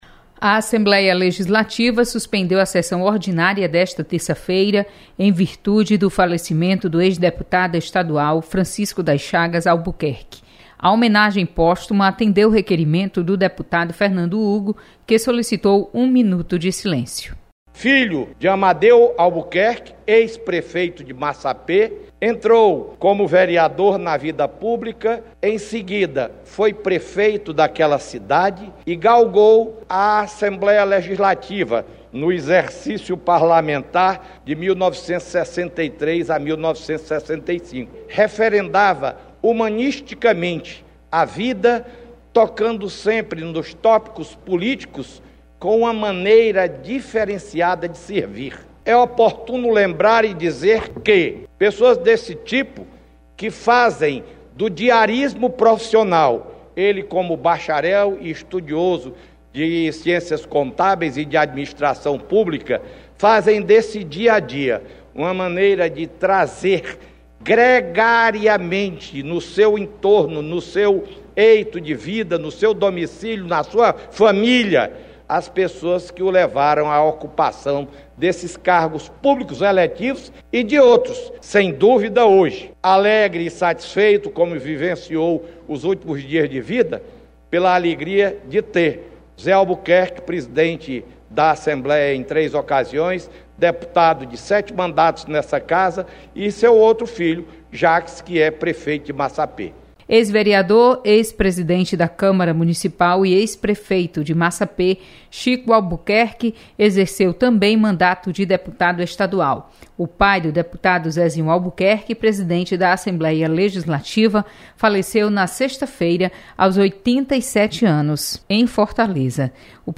Assembleia Legislativa suspende sessão em razão da morte do ex-deputado estadual Chico Albuquerque. Repórter